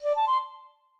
flute_dac1.ogg